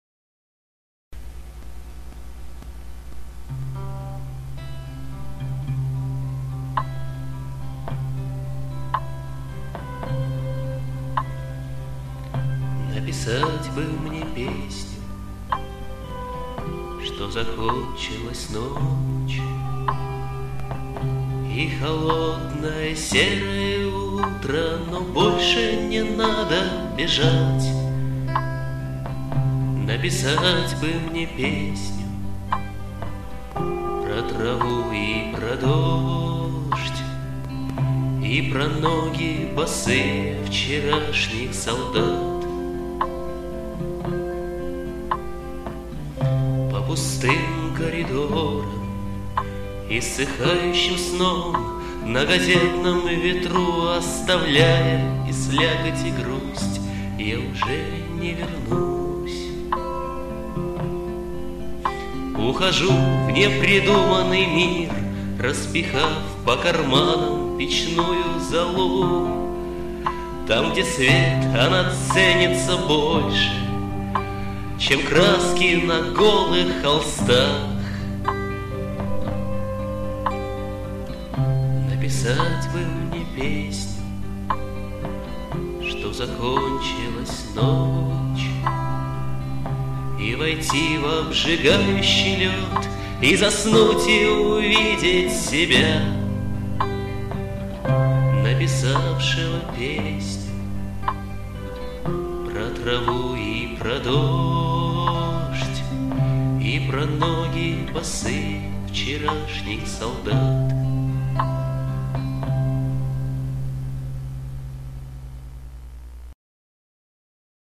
вокал, гитара